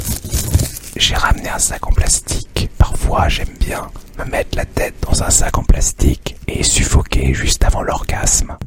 mistermv-asmr-1